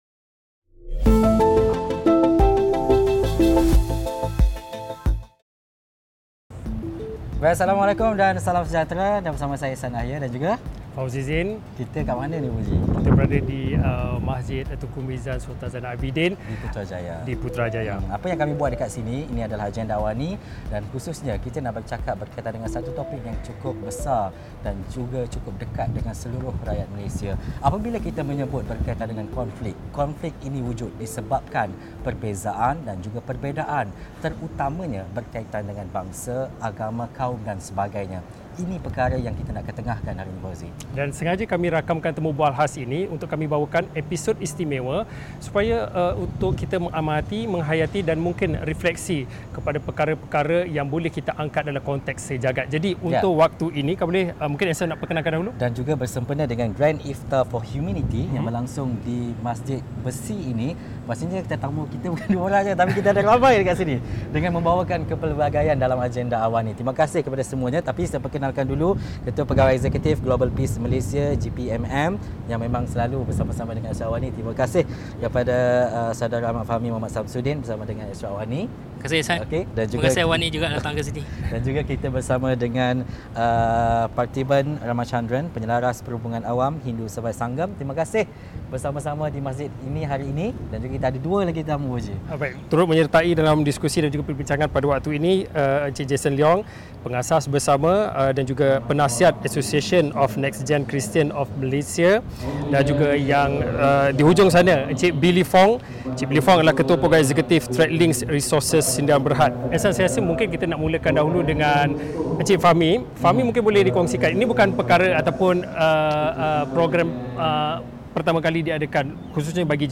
Diskusi 8.30 malam